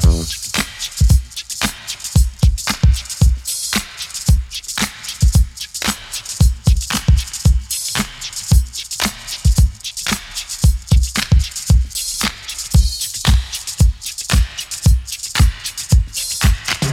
• 113 Bpm Modern Breakbeat C Key.wav
Free breakbeat - kick tuned to the C note. Loudest frequency: 2470Hz
113-bpm-modern-breakbeat-c-key-Me5.wav